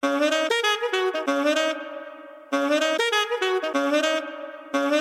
D#m_-_100_-_Sax_Multi_Loops
D#m_-_96_-_Sax_Loop